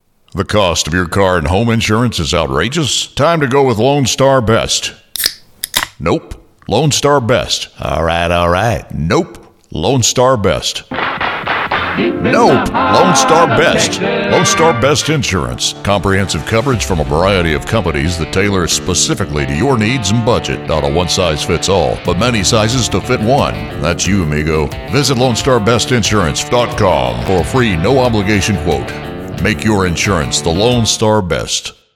Pro Voiceover, Radio/TV Production, Advertising, On-Air, Character Voices
Lonestar Best Insurance 30 sec Radio Ad (feat. character voices)
U.S. Southern, Texan, Midwestern